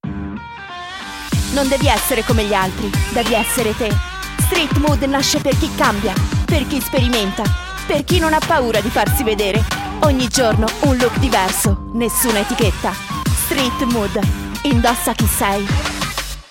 Ana dil spikerleri